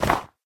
Sound / Minecraft / step / snow4.ogg
snow4.ogg